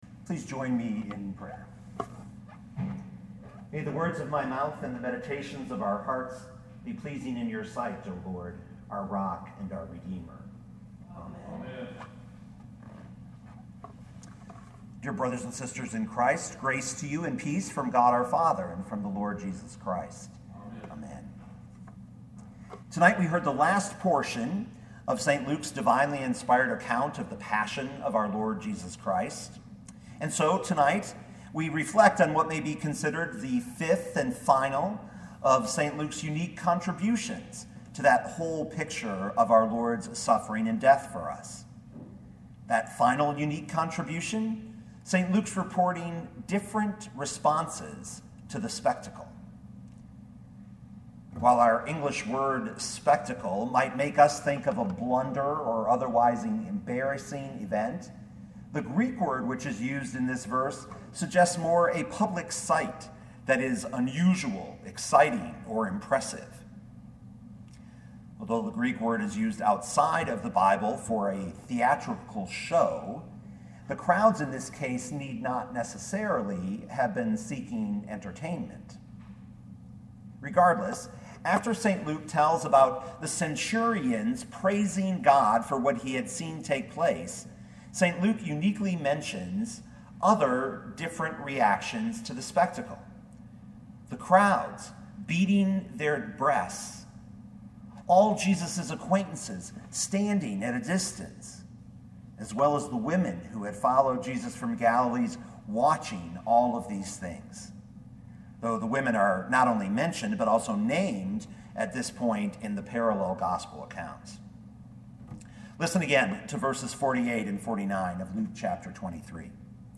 2019 Luke 23:48-49 Listen to the sermon with the player below, or, download the audio.